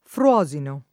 Fruosino [ fr U0@ ino ] → Frosino